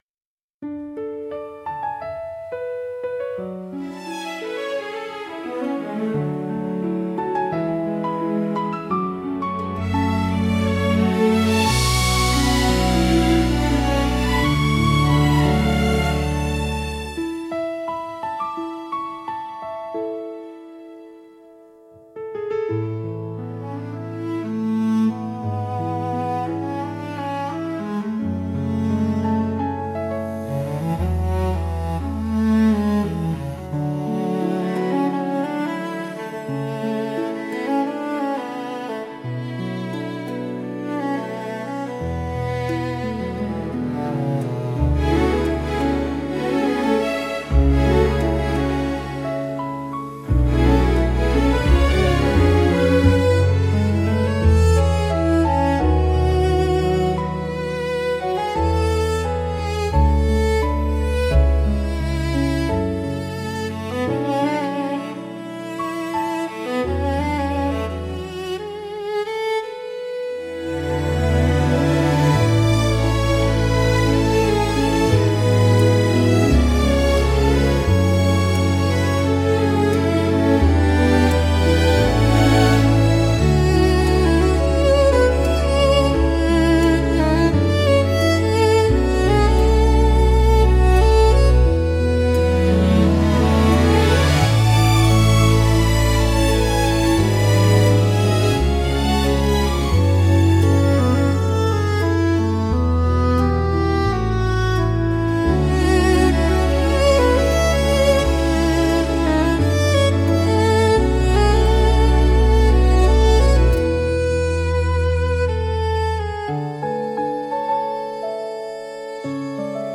オリジナルの朝ジャンルは、しっとりと穏やかでありながら希望に満ちた明るい曲調が特徴です。
優しいメロディと柔らかなアレンジが一日の始まりを穏やかに包み込み、聴く人に前向きな気持ちをもたらします。
静かで清々しい空気感を演出しつつ、心に明るい希望や期待を芽生えさせる効果があります。